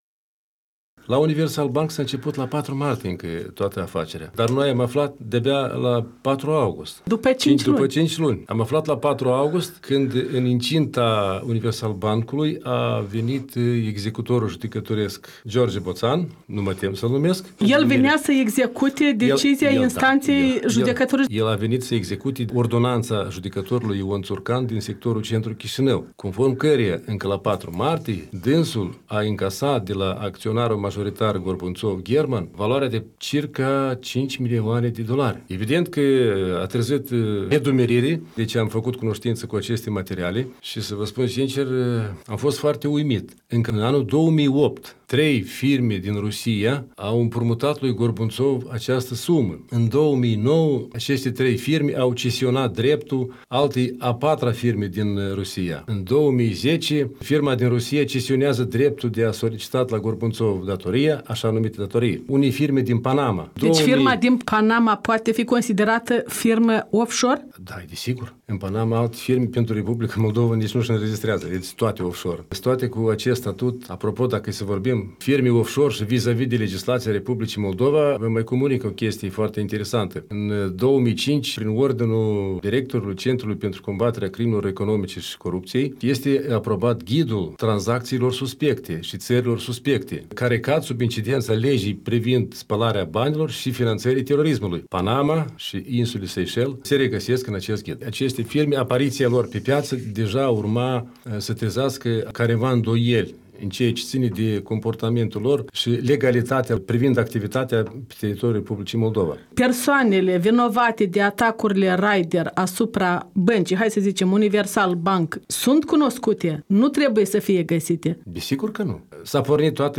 Au fost aceste atacuri menite să submineze securitatea statului? Interviu